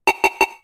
Targeted.wav